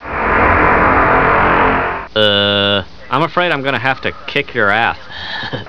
damage.wav